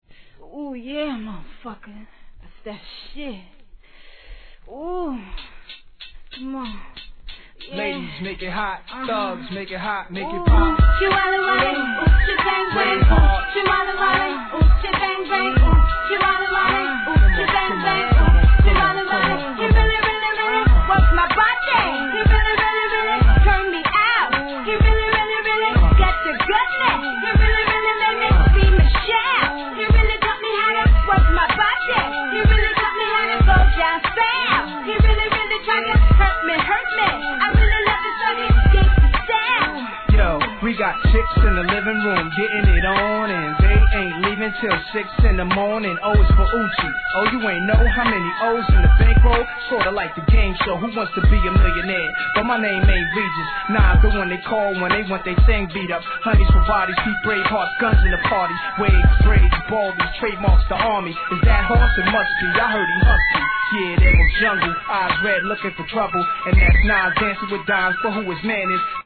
HIP HOP/R&B
怪しくも印象的なトラックでCLUB HIT!